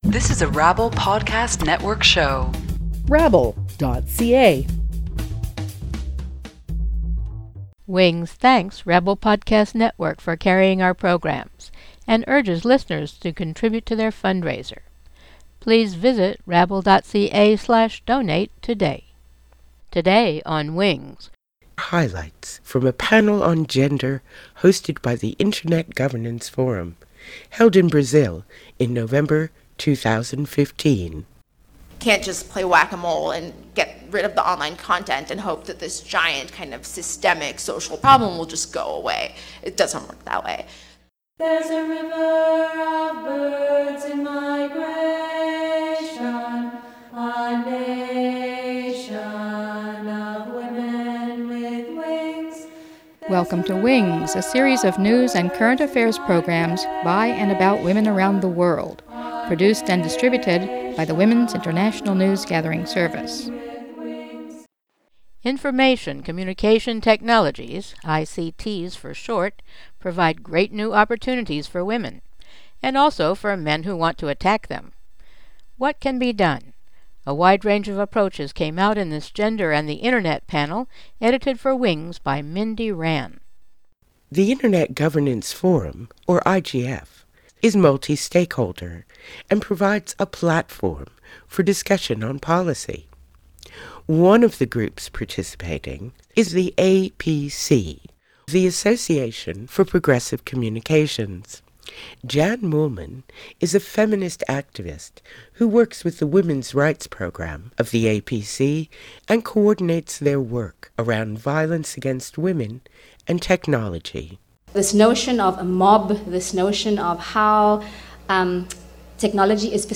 Download MP3 WINGS Net safety: Gender and the Internet April 5, 2016 | From a panel titled Gender and the Internet, held at the Internet Governance Forum in Brazil in November 2015, five speakers describe harmful and helpful uses of the Internet.